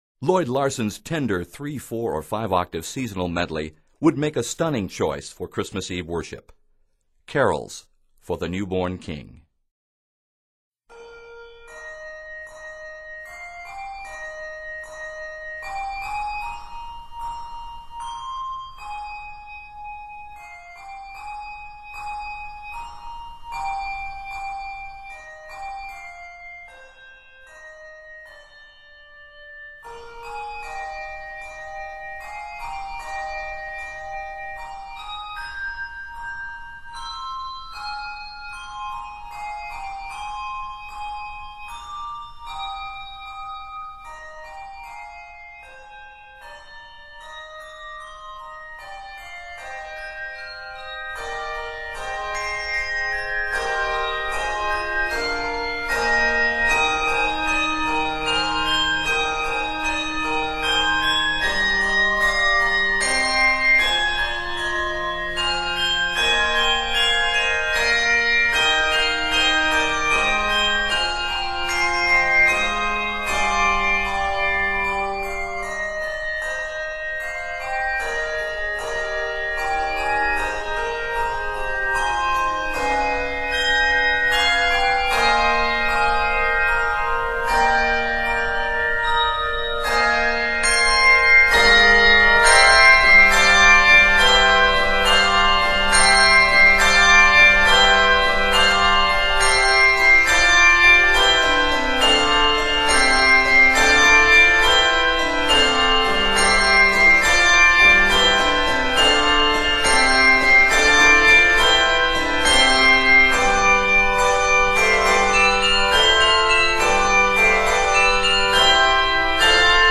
tender carol medley